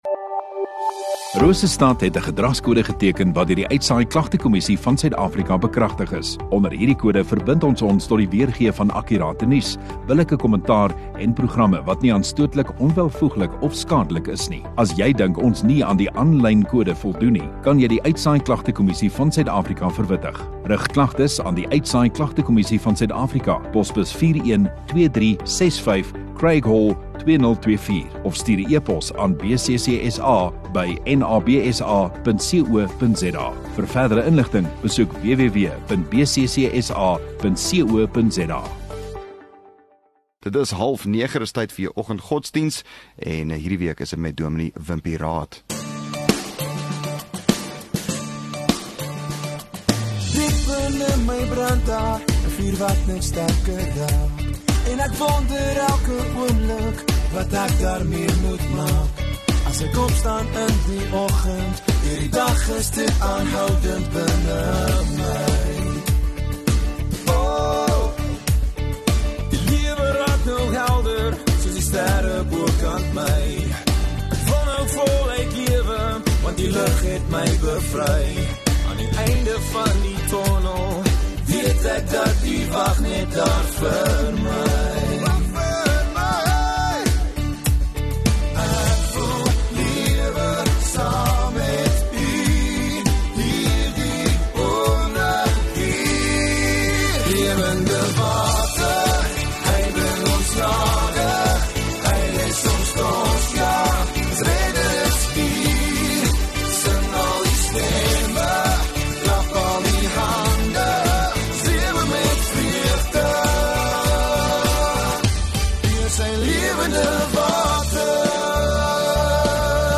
19 Feb Woensdag Oggenddiens